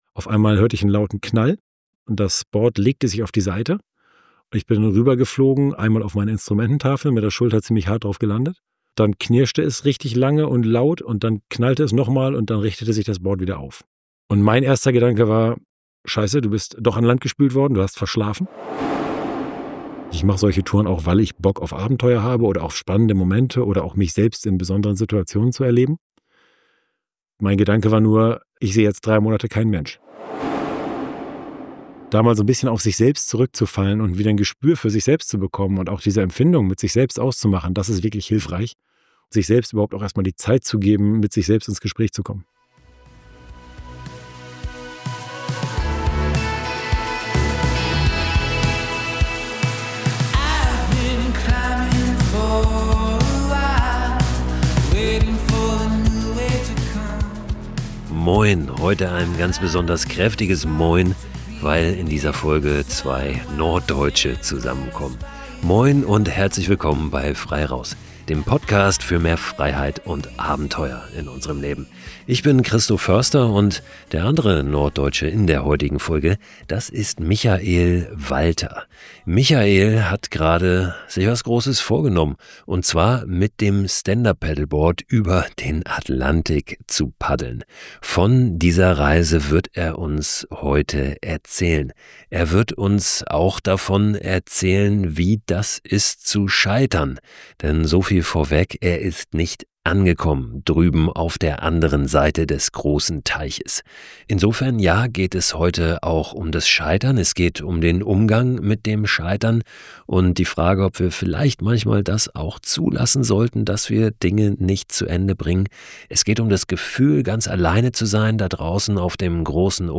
Ein sehr ehrlicher Talk, aus dem du selbst dann eine Menge mitnehmen kannst, wenn du selbst nicht unbedingt scharf darauf bist, morgen in See zu stechen.